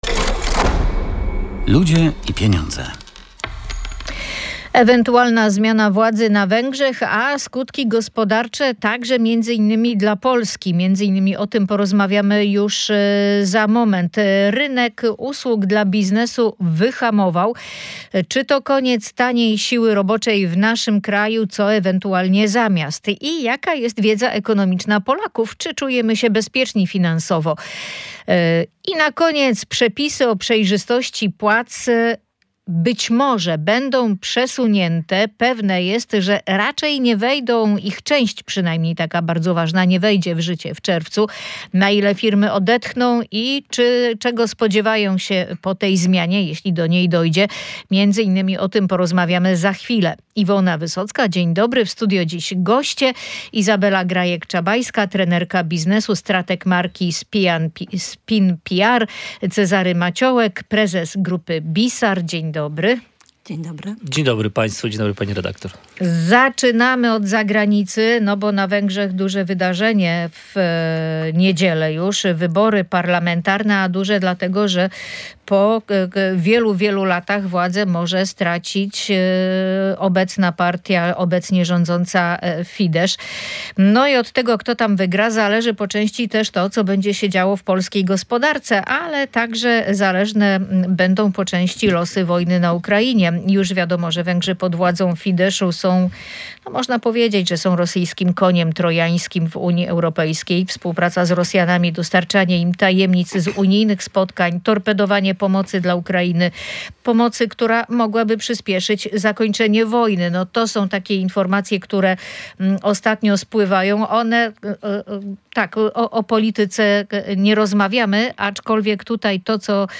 Posłuchaj audycji „Ludzie i Pieniądze